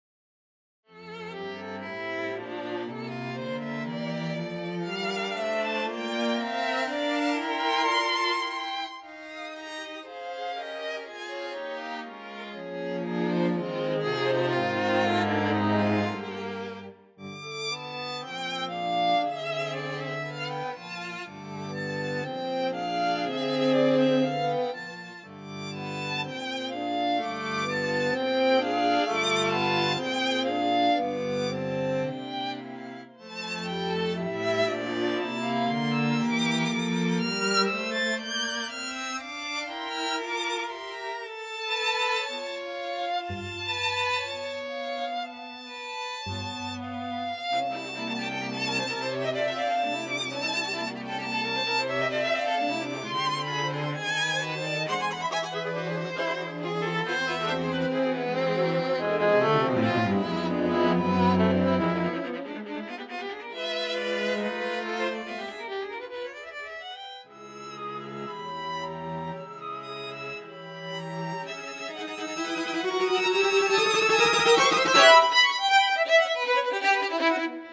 probably the best quartet mockup Ive heard